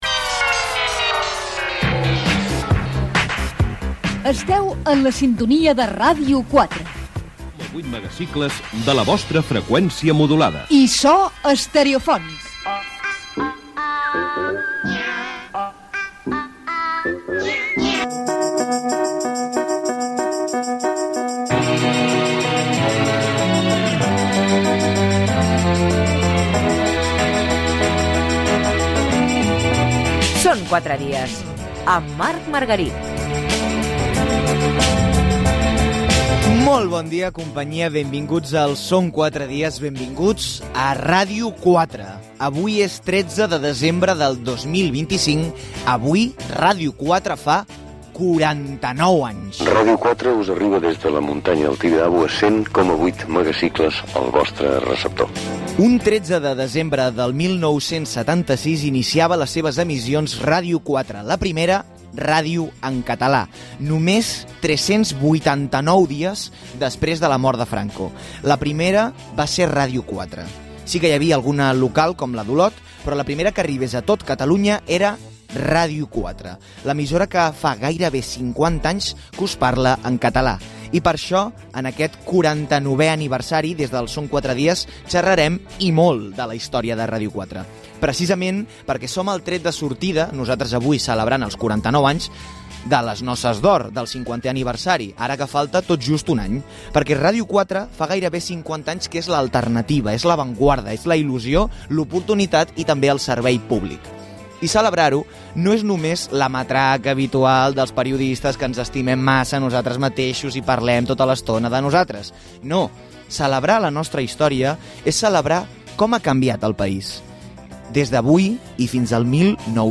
Indicatius de l'emissora i del programa, hora, data, presentació de la segona hora.
Gènere radiofònic Entreteniment